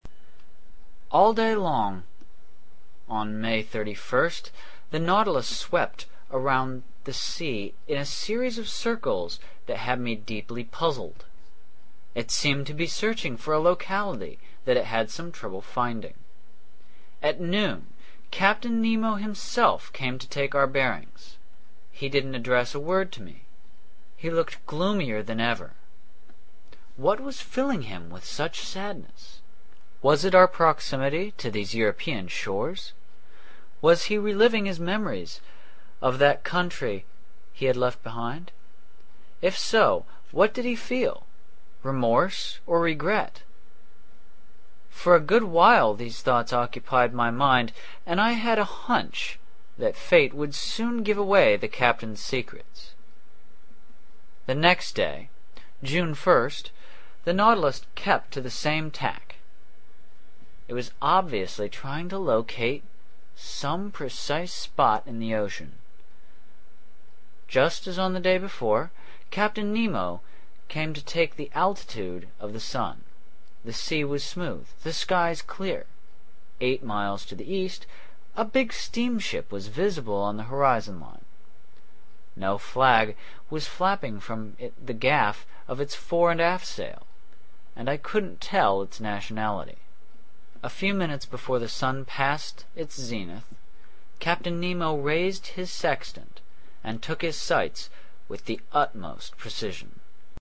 英语听书《海底两万里》第536期 第33章 北纬47.24度, 西经17.28度(11) 听力文件下载—在线英语听力室
在线英语听力室英语听书《海底两万里》第536期 第33章 北纬47.24度, 西经17.28度(11)的听力文件下载,《海底两万里》中英双语有声读物附MP3下载